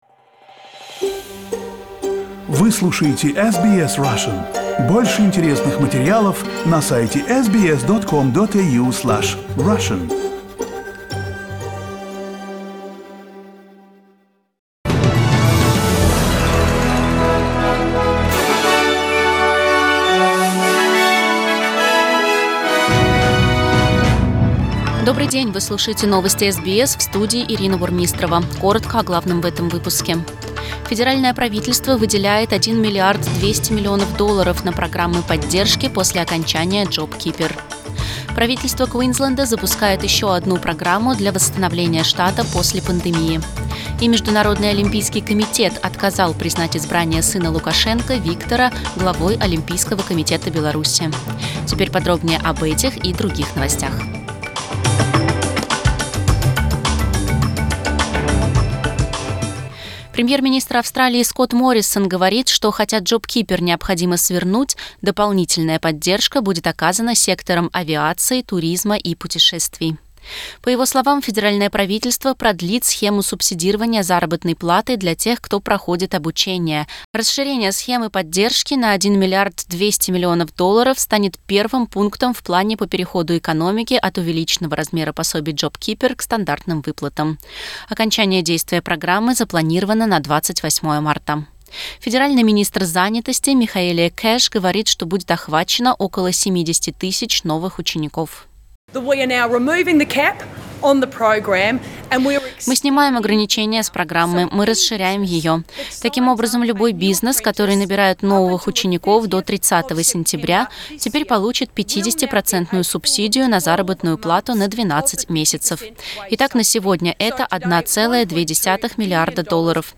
Новостной выпуск за 9 марта